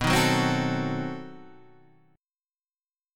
B7b9 chord {7 6 4 5 7 5} chord